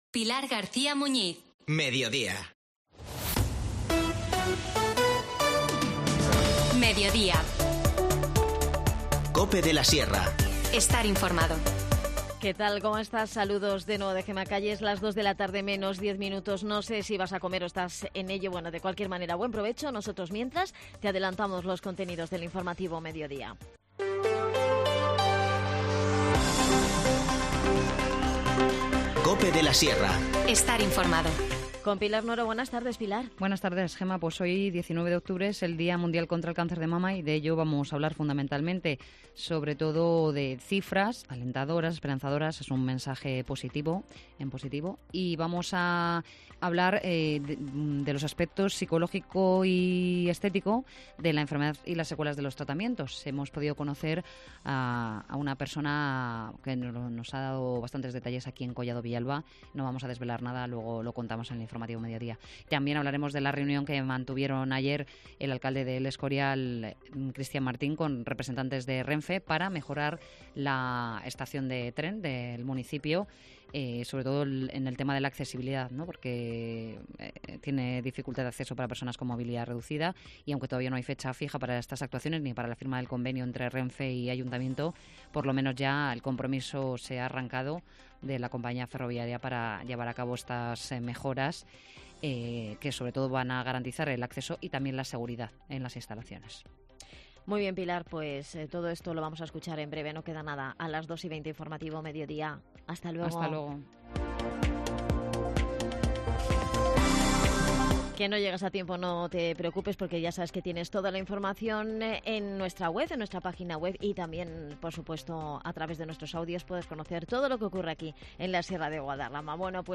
Charlamos con